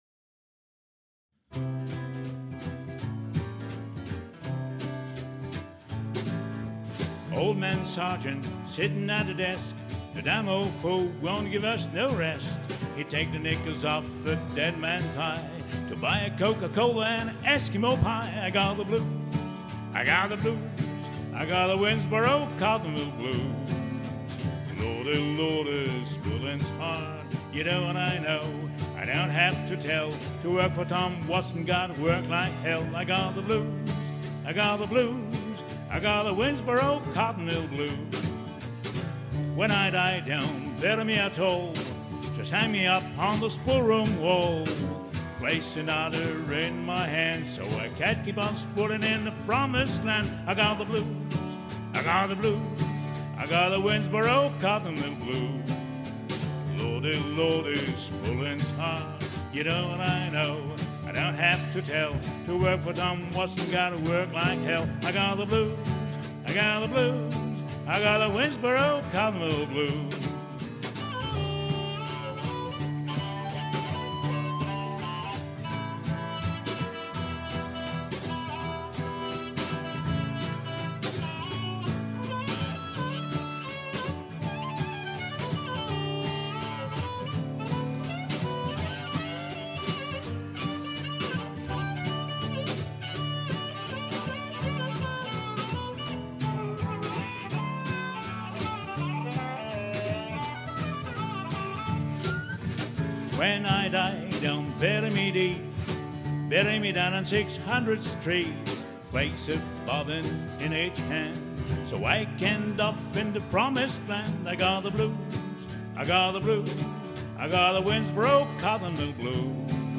winnsborrowcottonblues.mp3